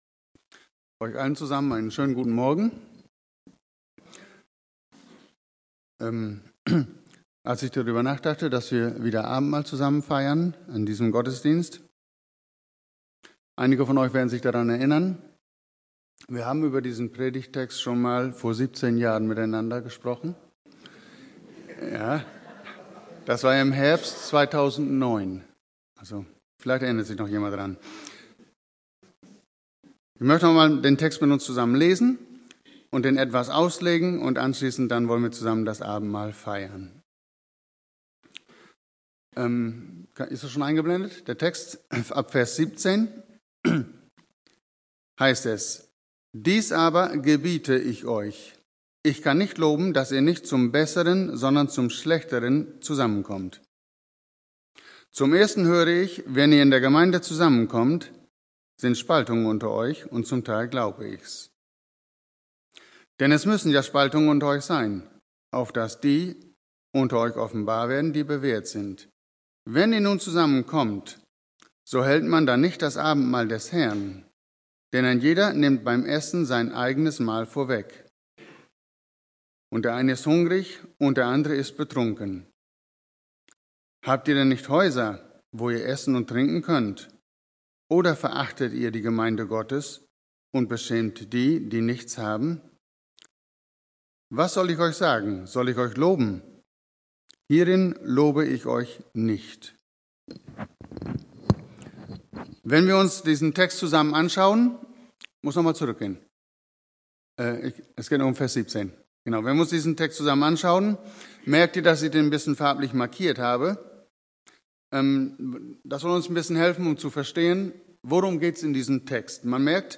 Abendmahl Prediger: Gastprediger Predigten Serien Gastprediger Details Datum: 02.03.2026 Bibelstelle: 1. Korinther 11, 17-34